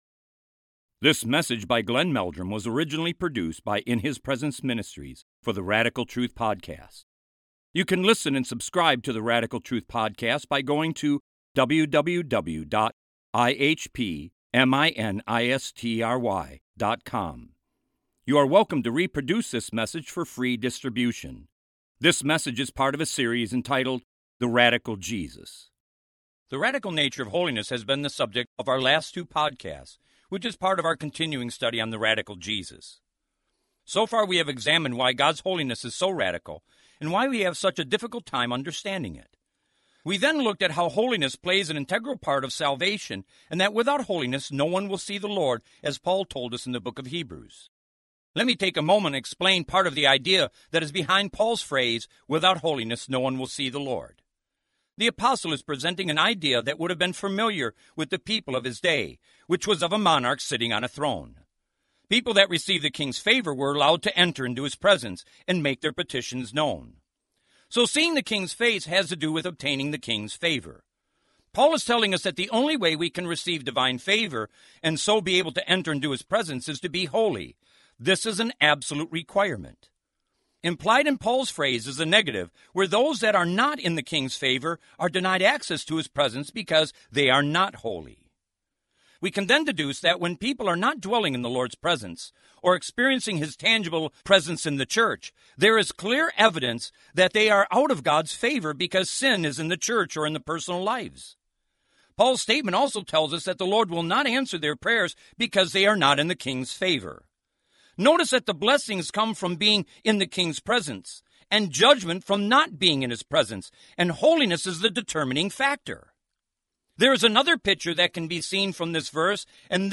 In this sermon, the preacher emphasizes the significance of the crucifixion of Jesus Christ. He highlights the agony and rejection that Jesus experienced on the cross as he bore the weight of our sins. The preacher argues that sin is not a small matter, but rather an immense evil that we must flee from.